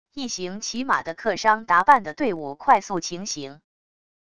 一行骑马的客商打扮的队伍快速情形wav音频